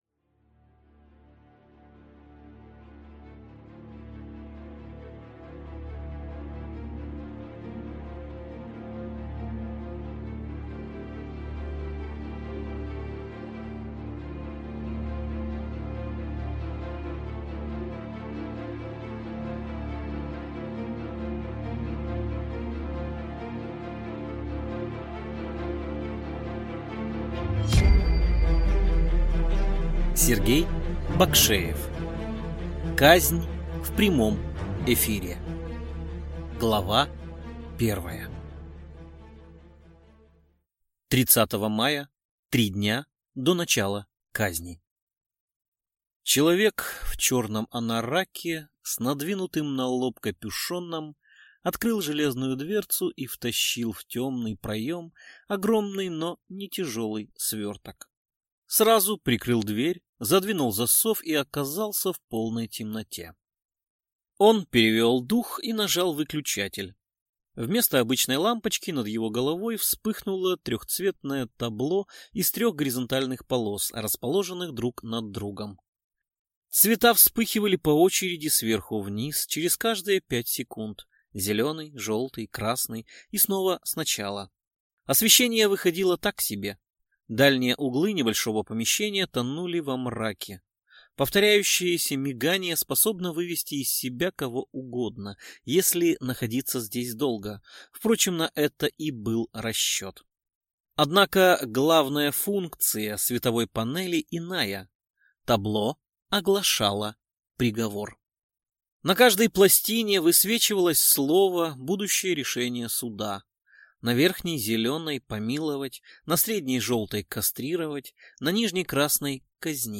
Аудиокнига Казнь в прямом эфире | Библиотека аудиокниг